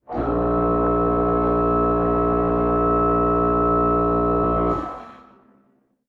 moontruckwork1.wav